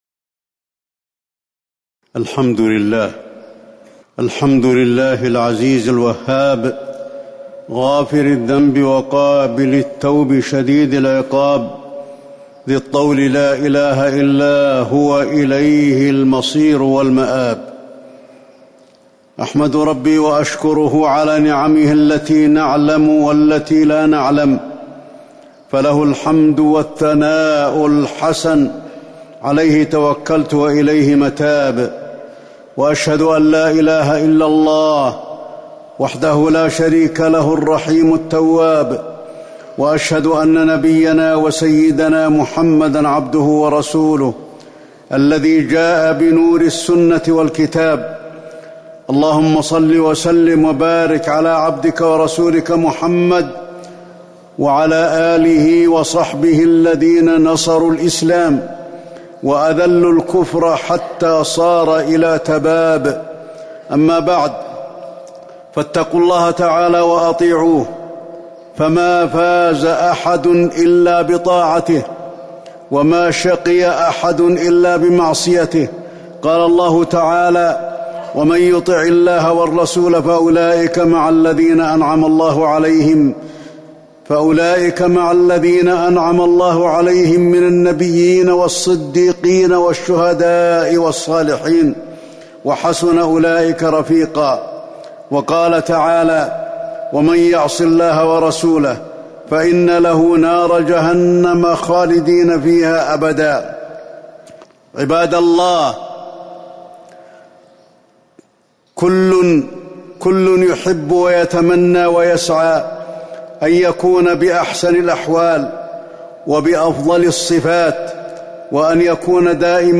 تاريخ النشر ٢٢ رجب ١٤٤٠ هـ المكان: المسجد النبوي الشيخ: فضيلة الشيخ د. علي بن عبدالرحمن الحذيفي فضيلة الشيخ د. علي بن عبدالرحمن الحذيفي ومن يؤتى الحكمة فقد أوتي خيرا كثيرا